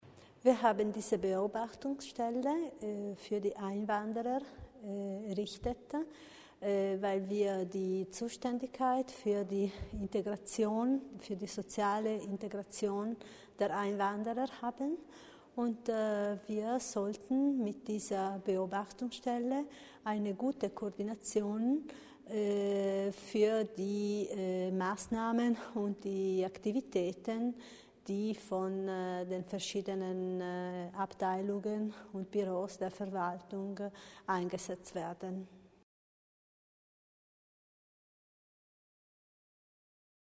Landerätin Repetto über die Ziele der Koordinierungsstelle für Einwanderung